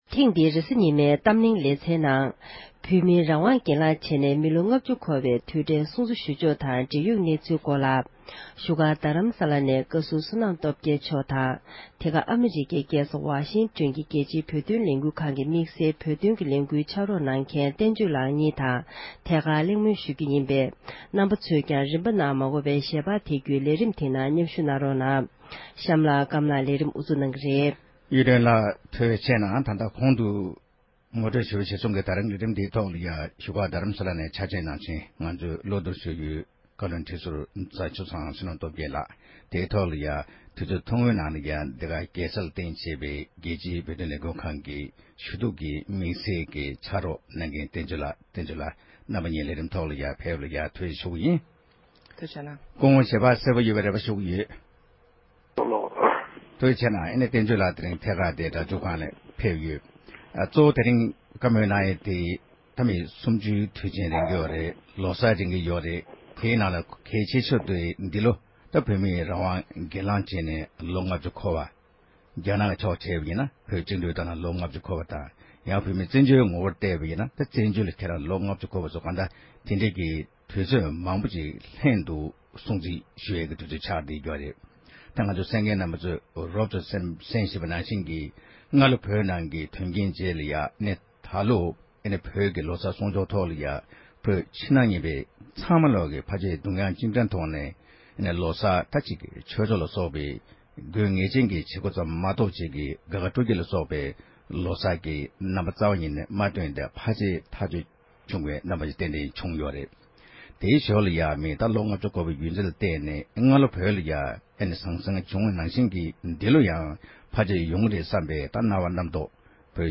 གླེང་མོལ་གནང་བ་ཞིག་གསན་རོགས་ཞུ༎